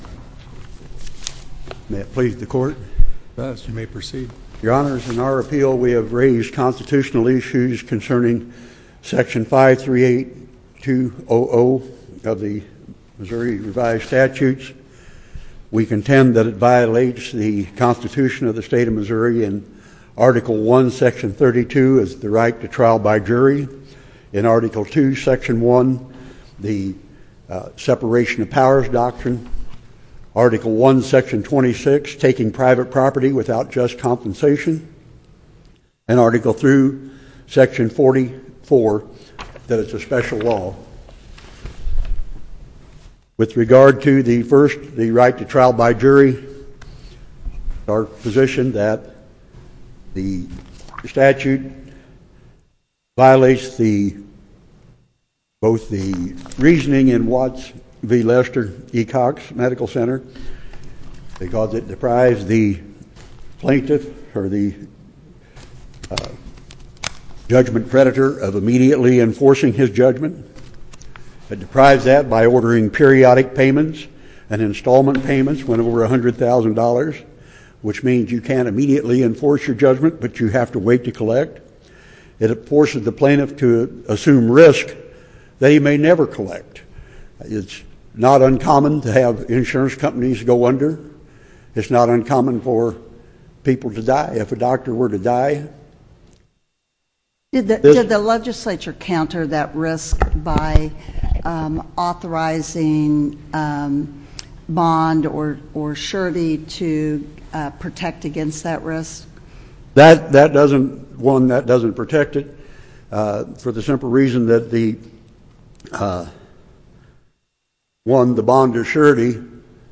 link to MP3 audio file of arguments in SC96528
Challenges to constitutional validity of statute under which future damages are reduced and subjected to periodic payments; sufficiency of evidence supporting verdict Listen to the oral argument